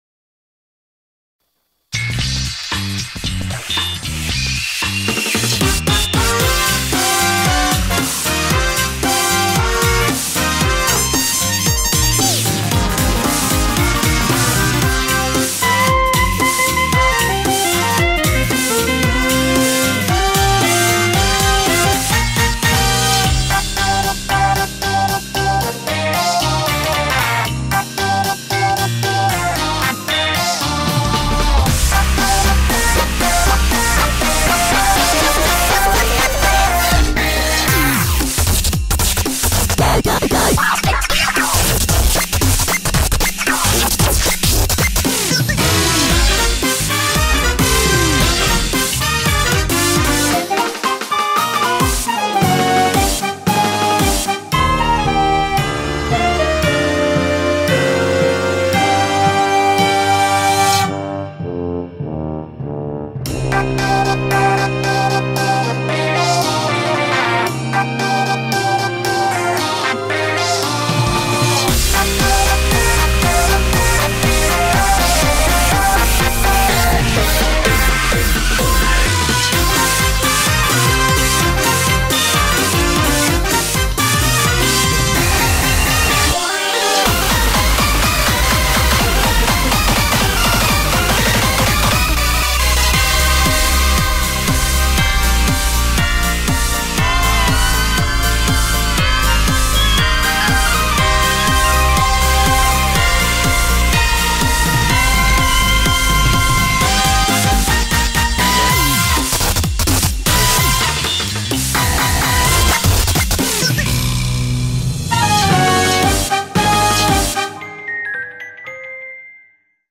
BPM90-128
Audio QualityPerfect (High Quality)
CommentsBPM distribution (WORLD): 90~114~128